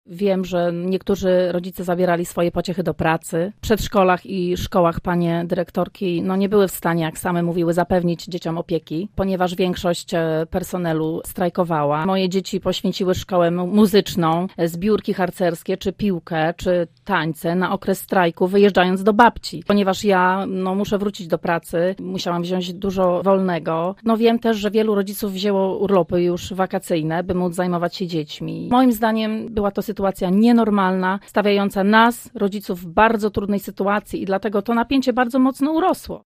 Jak dodał nasz poranny gość, grupa na fb powstała dlatego, że rodzice są niezadowoleni z formy strajku.